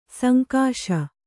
♪ sankāśa